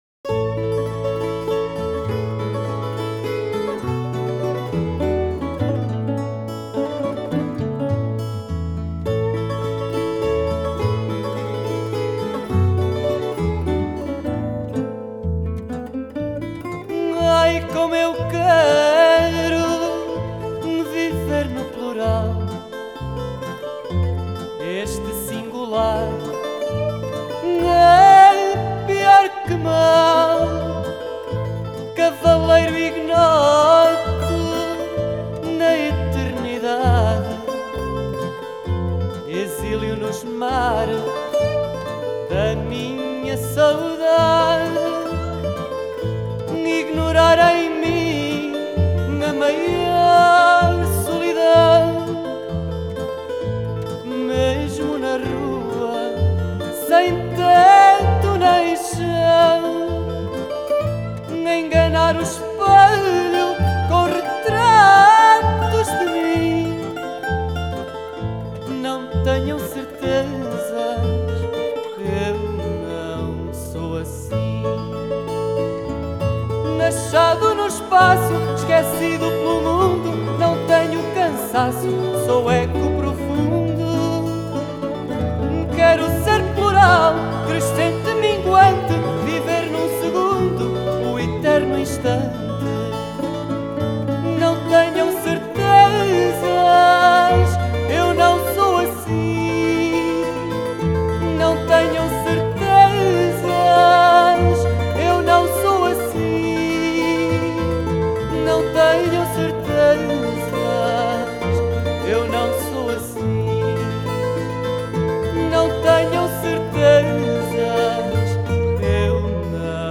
Genre: Folk, World, Fado